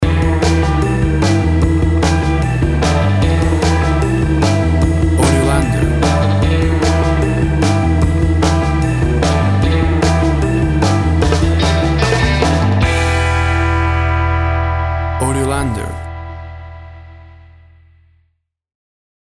Tempo (BPM) 150